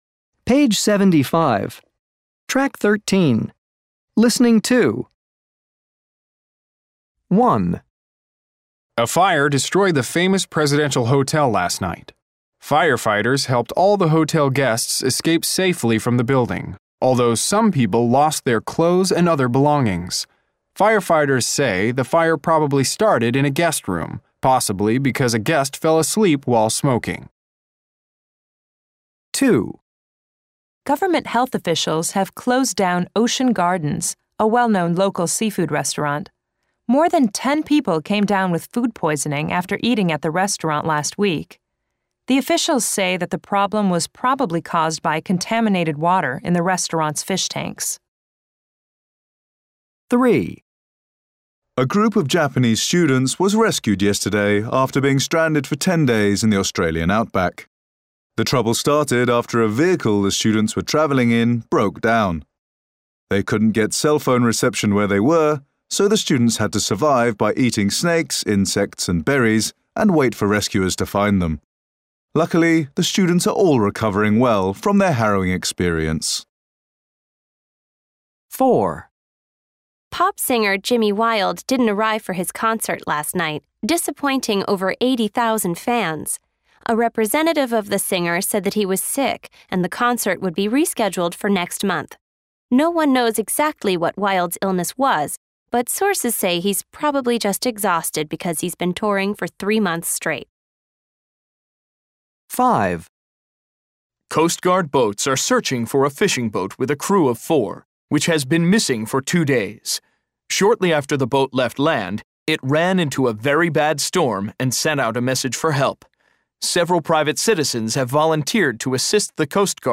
Read these newspaper headlines and listen to the news reports. Are the headlines correct? check the correct answer.